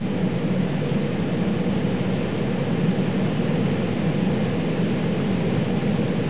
دانلود آهنگ طیاره 55 از افکت صوتی حمل و نقل
جلوه های صوتی
دانلود صدای طیاره 55 از ساعد نیوز با لینک مستقیم و کیفیت بالا